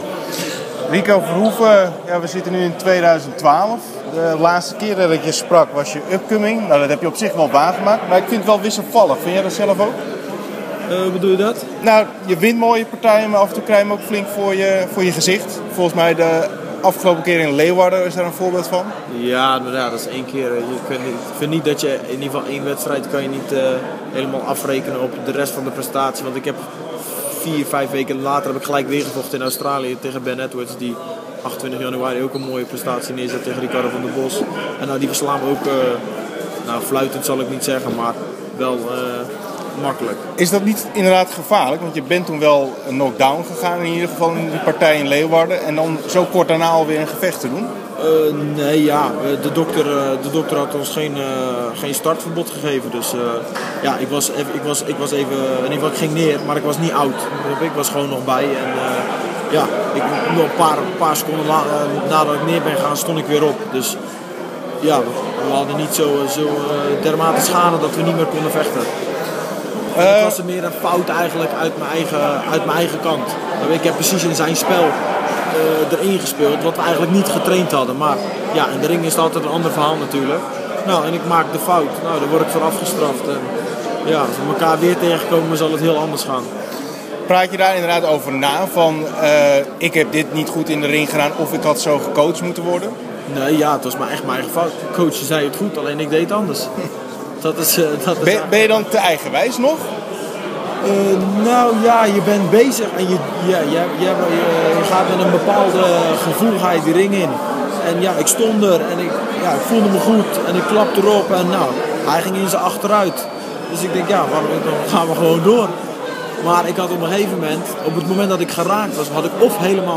Interview 2015 Verhoeven Glory 26 gewonnen tegen Benjamin Adegbuyi.